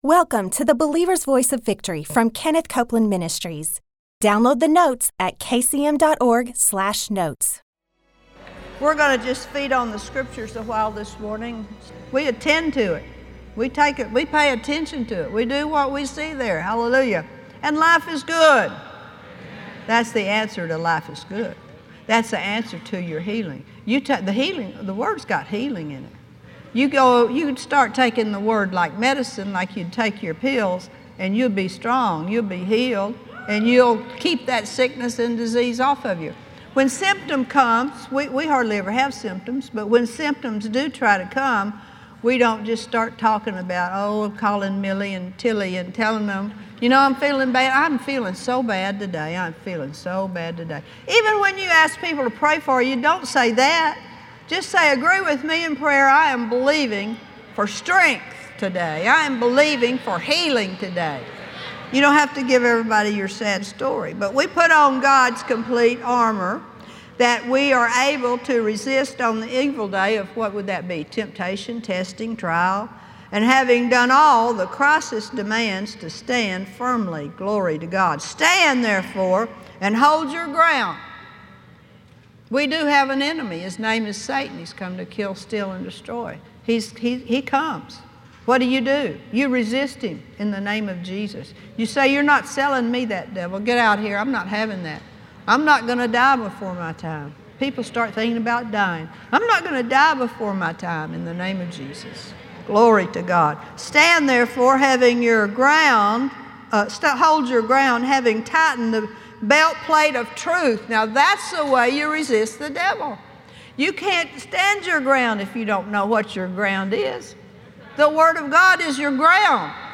a teaching on let the redeemed of the Lord say so.